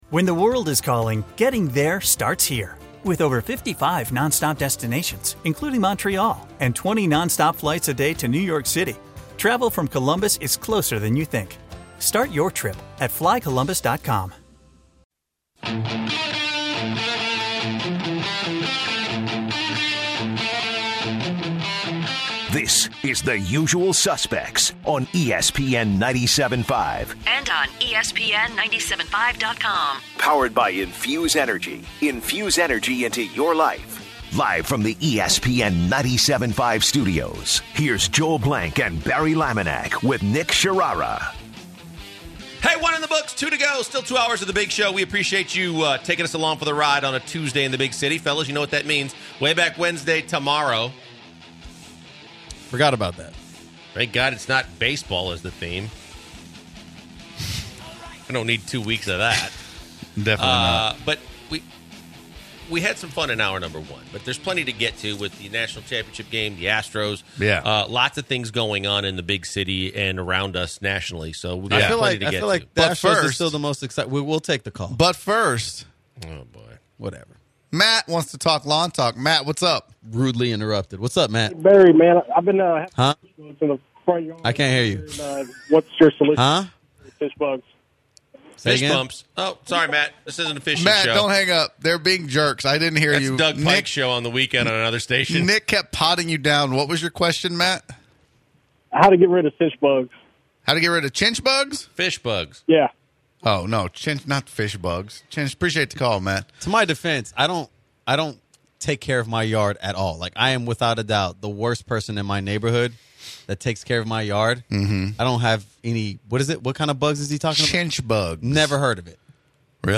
This hour of the show is mostly dedicated to baseball and talking about the Astros. A comment from a caller sparks a debate about whether OKC has a better chance in the playoffs against the Rockets or the Nuggets.